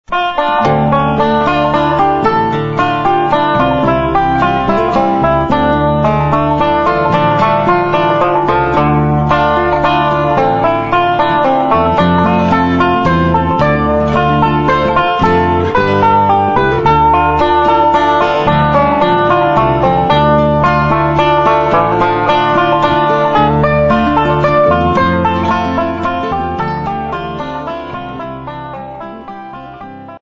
5-String Banjo .mp3 Samples
5-String Banjo Samples - Intermediate Level